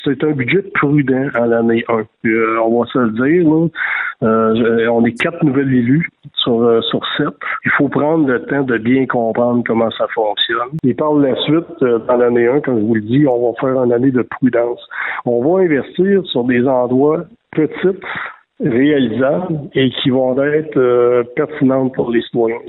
C’est ce qu’a soutenu en entrevue, le nouveau maire, François Pépin.